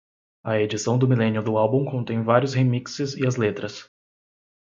/e.d͡ʒiˈsɐ̃w̃/